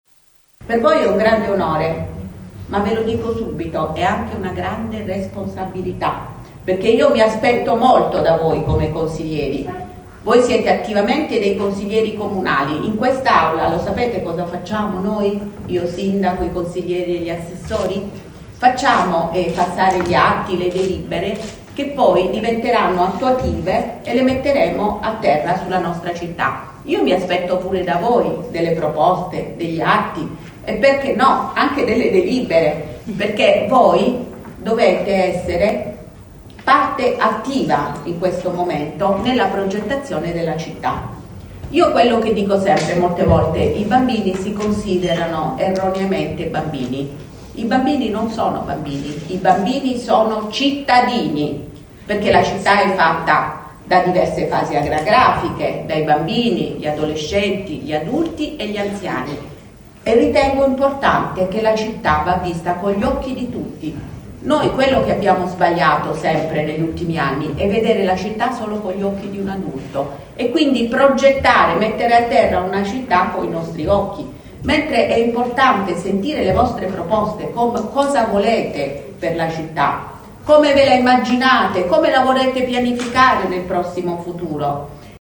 LATINA – Rinnovato questa mattina il consiglio dei bambini e delle bambine del Comune di Latina.